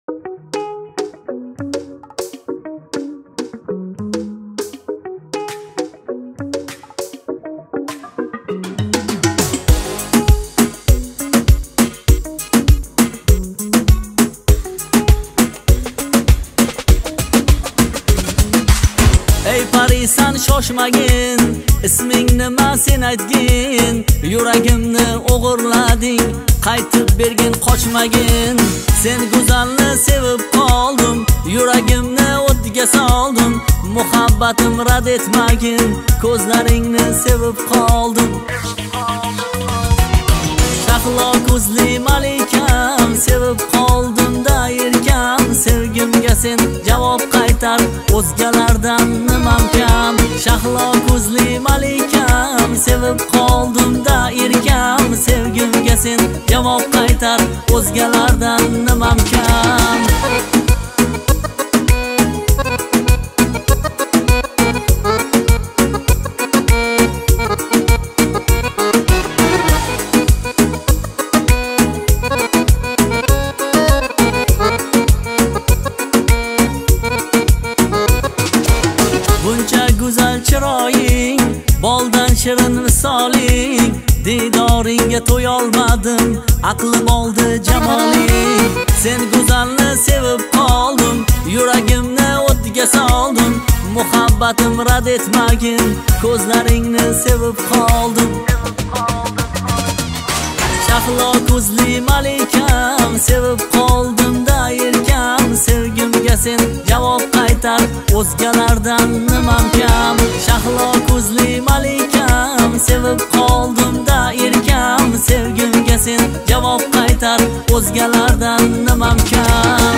• Категория: Узбекские песни